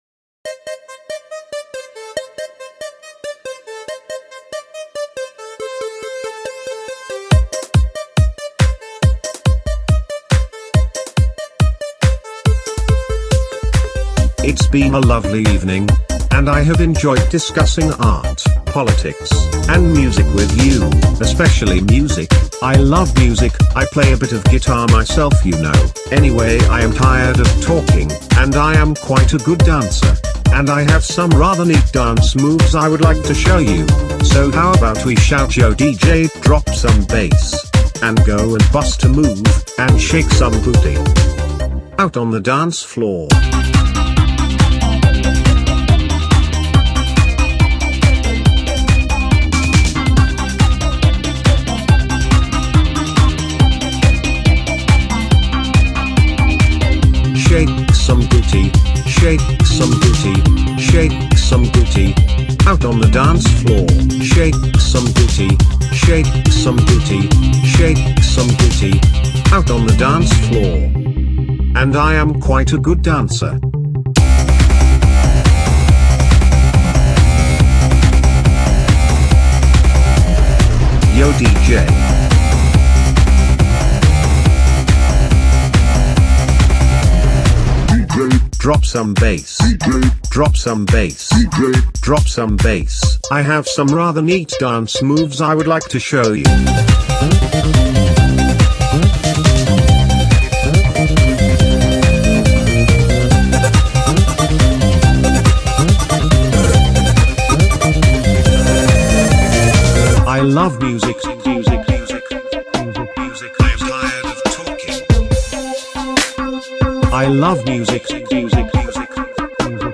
Lyric consists of only one sentence.